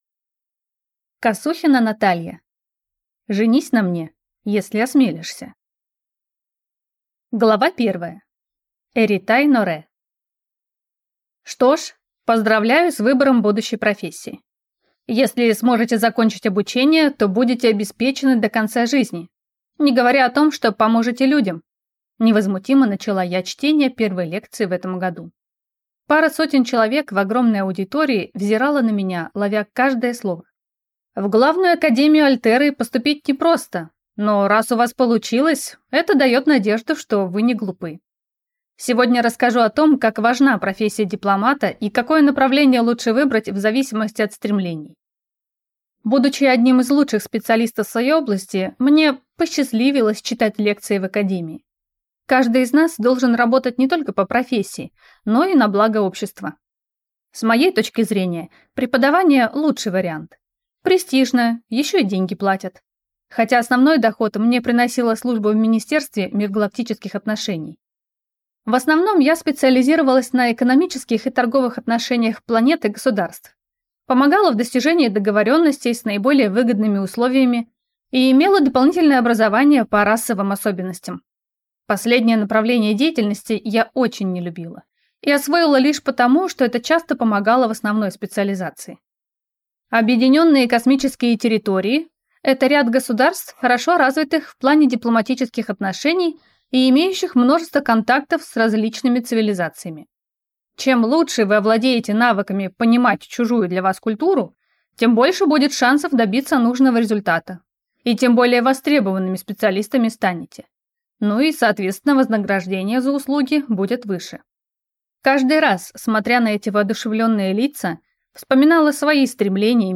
Аудиокнига Женись на мне, если осмелишься | Библиотека аудиокниг